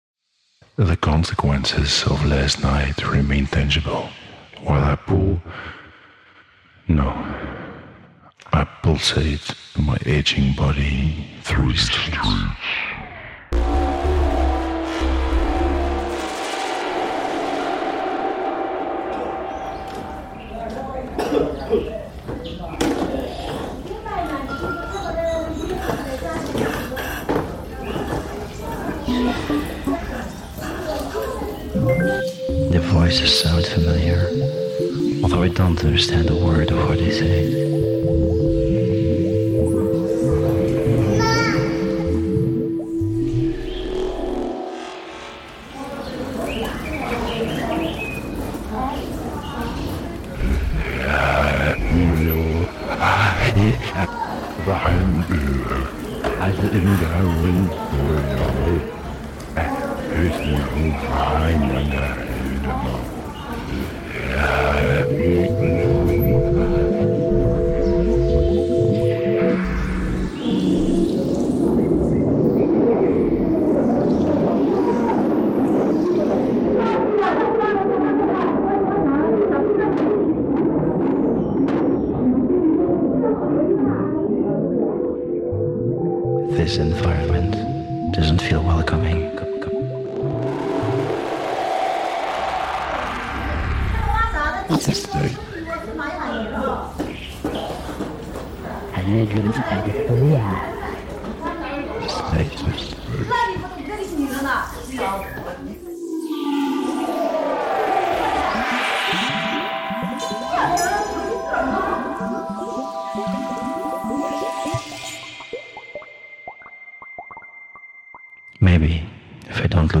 Wuhu old town soundscape reimagined